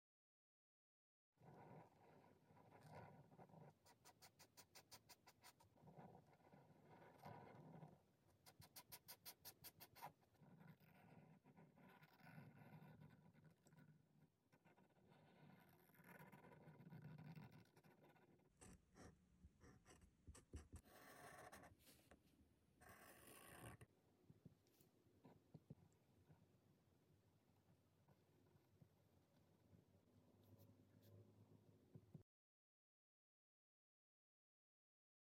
malen-doodle-ASMR